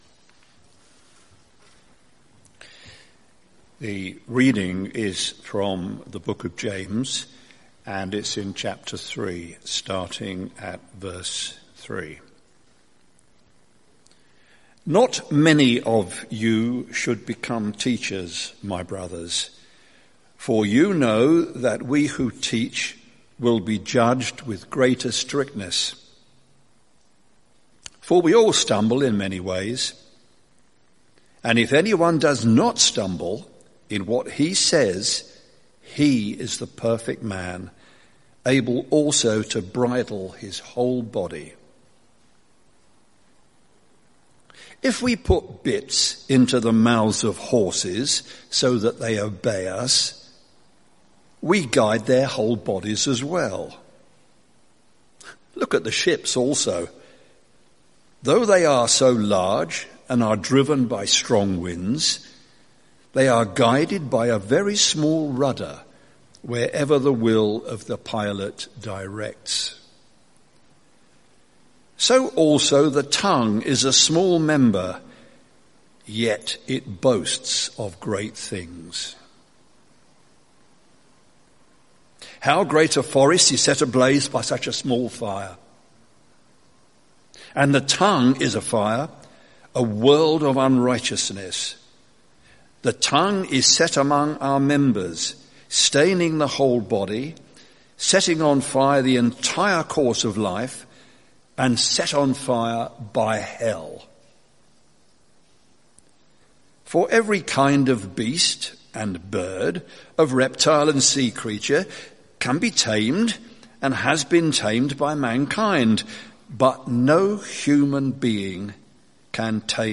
Sermon Series: Taming the Tongue | Sermon Title: Keeping out of Trouble